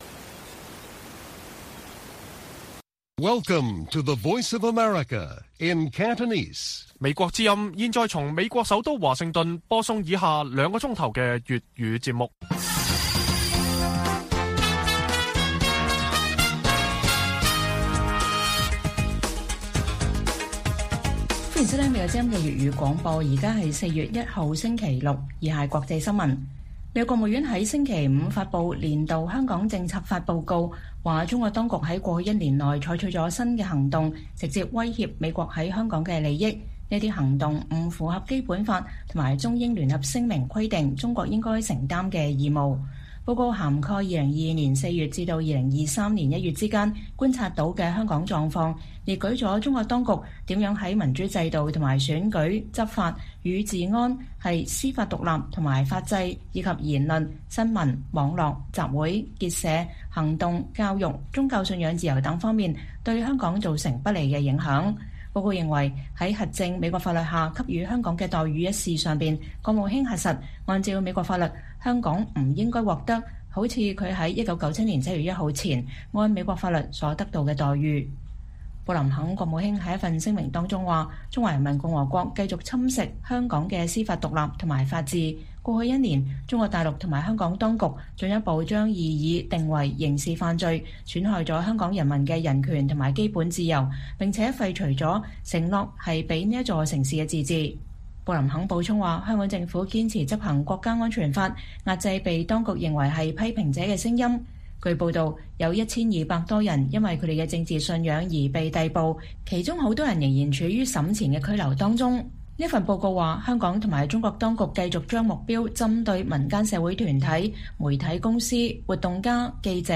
粵語新聞 晚上9-10點: 美國國務院發布2023年《香港政策法報告》